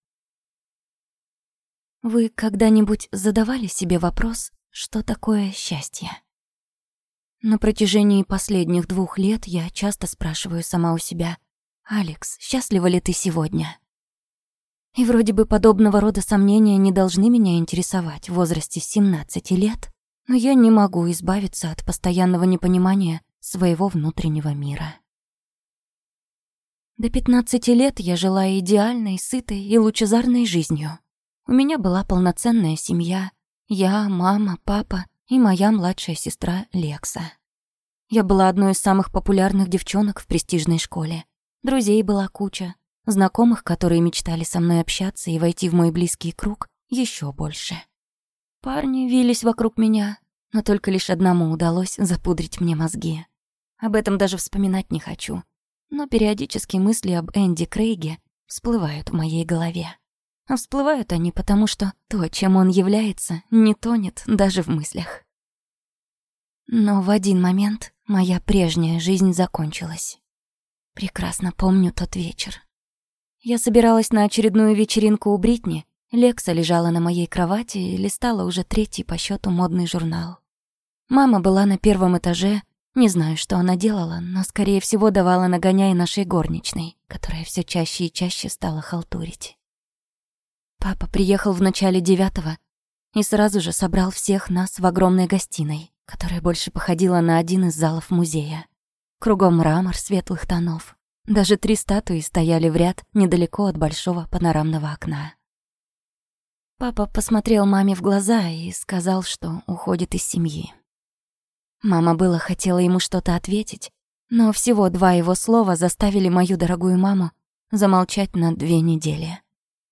Аудиокнига Туман | Библиотека аудиокниг